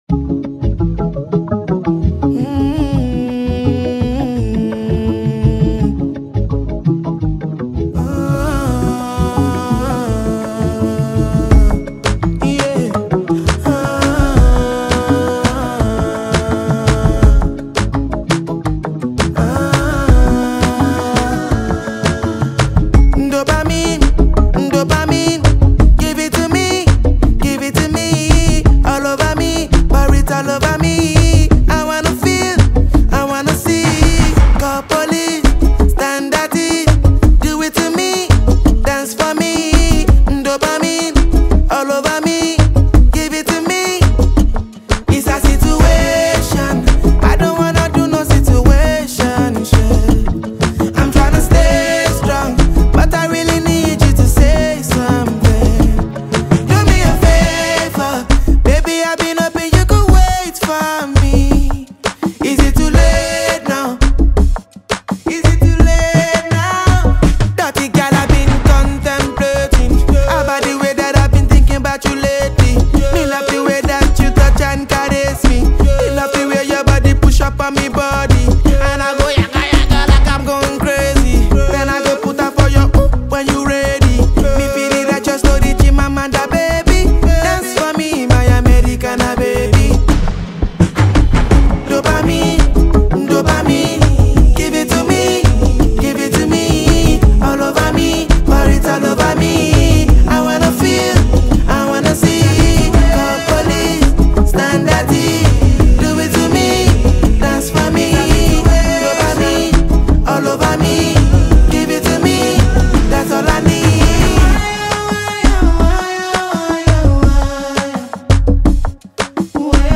is a smooth, addictive tune